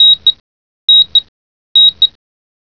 BEEPSLOO.WAV